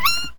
sounds / monsters / rat / death_3.ogg
death_3.ogg